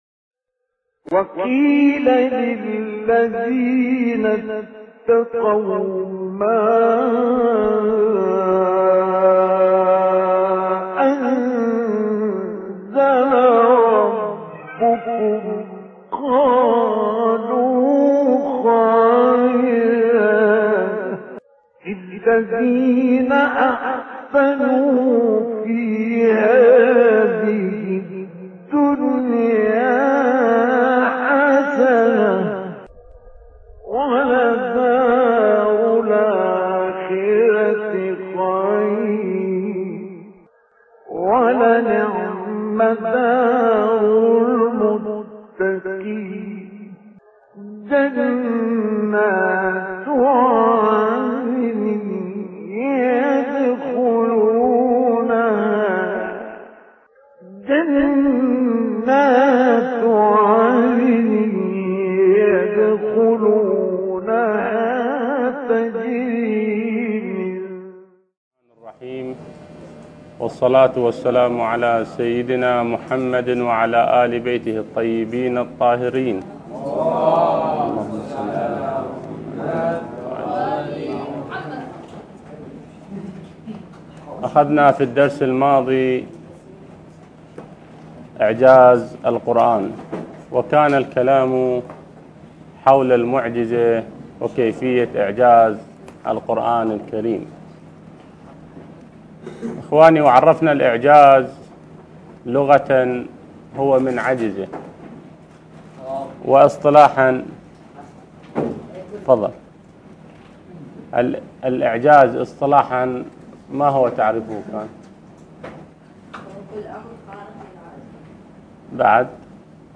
الدرس السابع المحكم والمتشابه - لحفظ الملف في مجلد خاص اضغط بالزر الأيمن هنا ثم اختر (حفظ الهدف باسم - Save Target As) واختر المكان المناسب